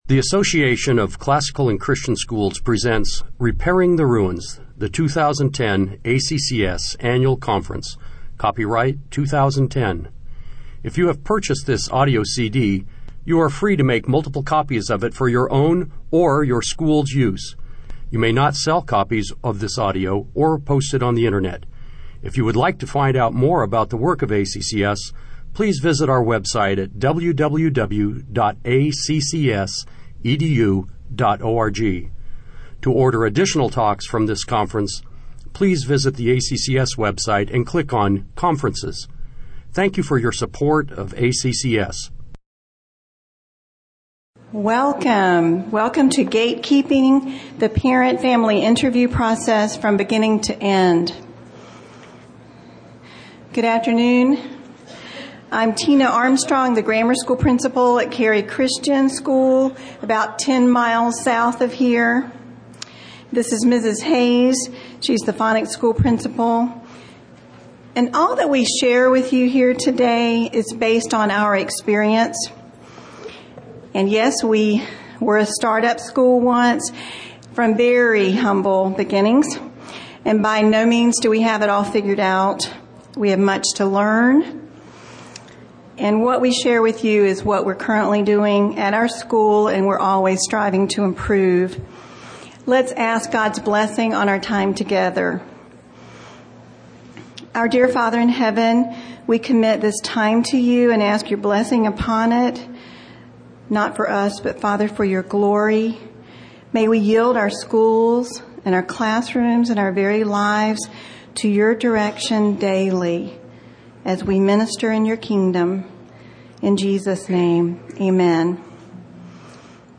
2010 Workshop Talk | 1:03:43 | Leadership & Strategic